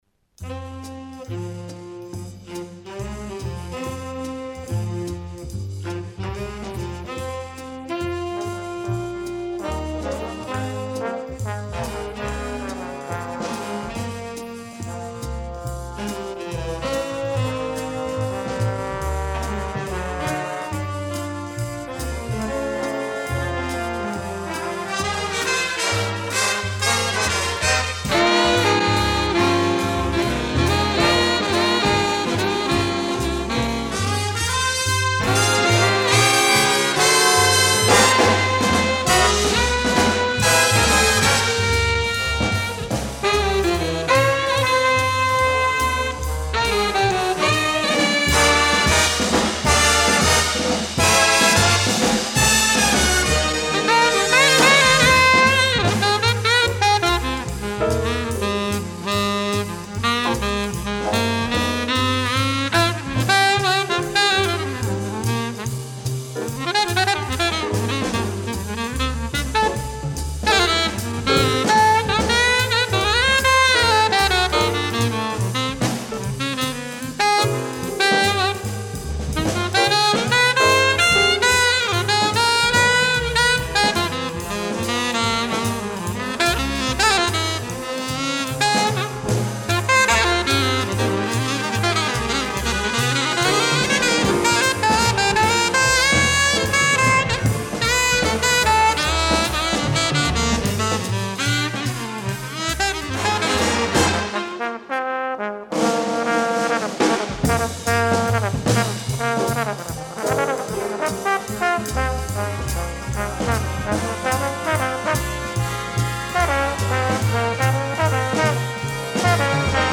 Новые поступления СТАРОГО РАДИО. Инструментальная музыка советских и зарубежных композиторов (ч. 9-я).